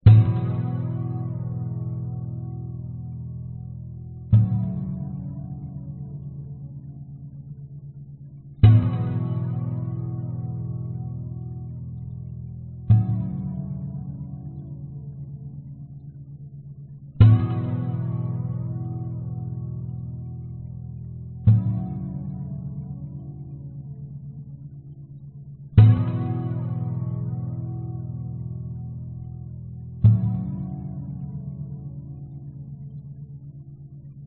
描述：这是一个在原声GTR上演奏的两个和弦的主题，并通过GuitarRig播放。
Tag: 原声 寒冷 实验性 吉他